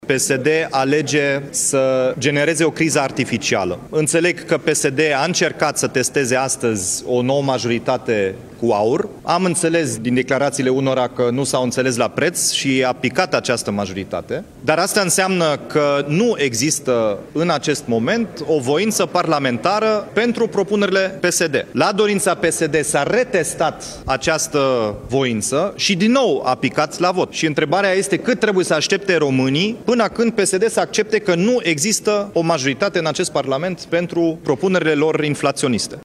Președintele USR, Dominic Fritz, explică de ce PSD a eșuat în încercarea de a obține voturile AUR.